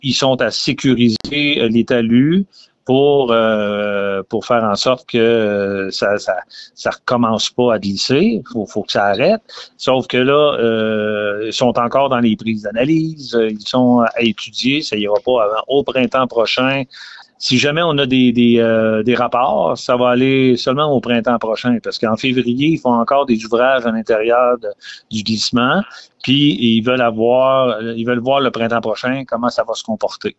Lors de l’entrevue avec VIA 90.5 FM, M. Gélinas a fait une mise à jour de ce glissement de terrain survenu en mai dernier.